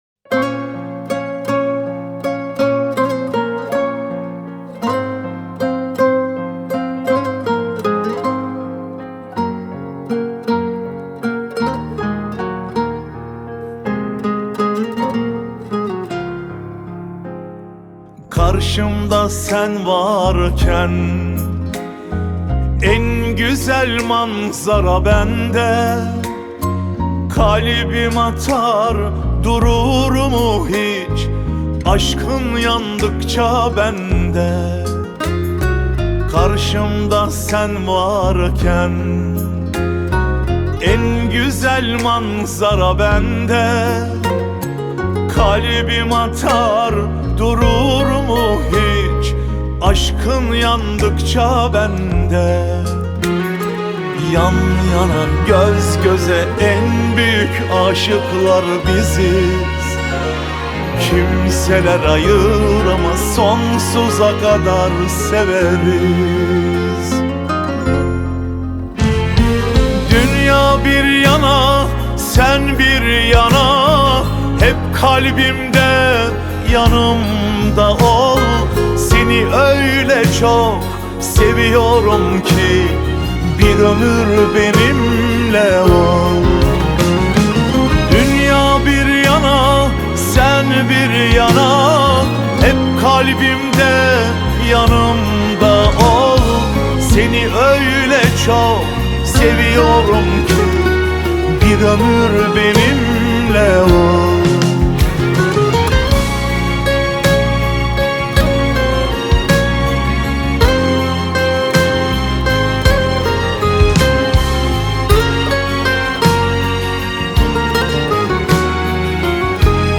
آهنگ ترکیه ای